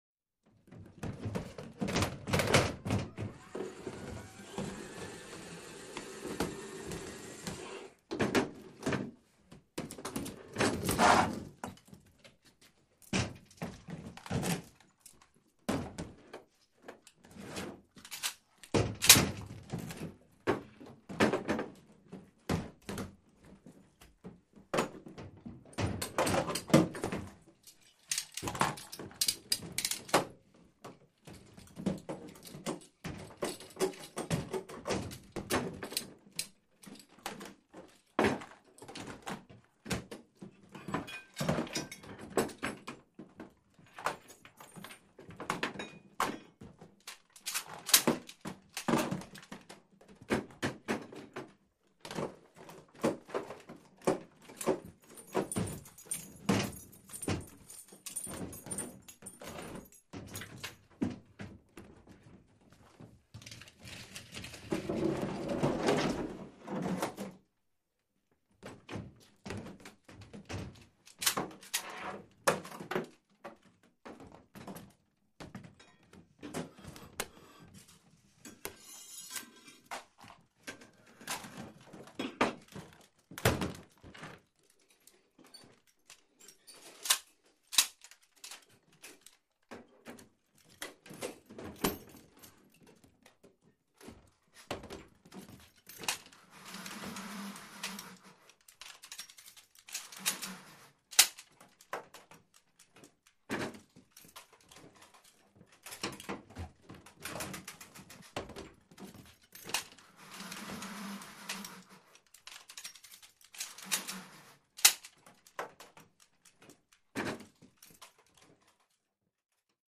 Ax Hits | Sneak On The Lot